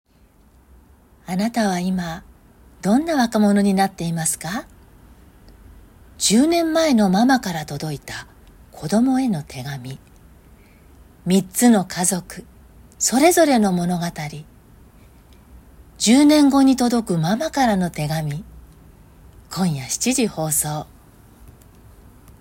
ナレーション音源サンプル　🔽
【優しい系ナレーション】